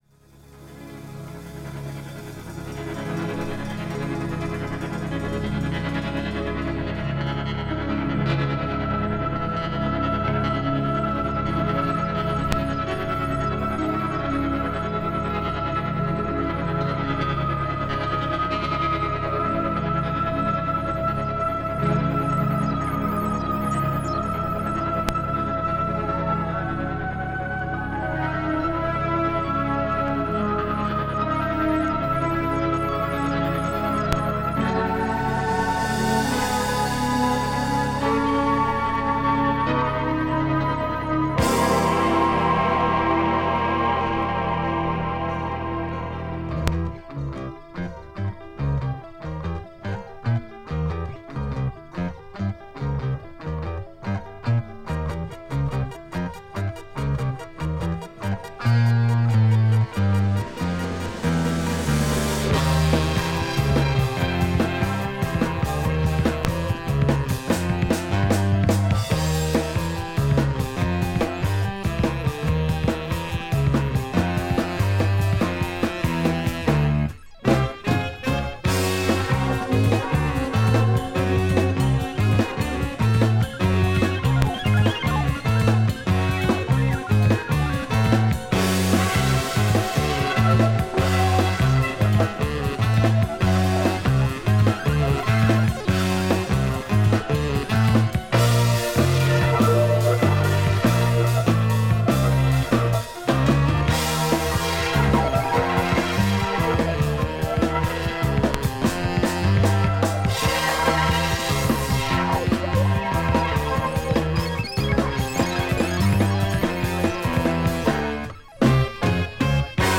【COSMIC】 【JAZZ ROCK】
Synth rock from Switzerland!
There is a very small amount of dust noise.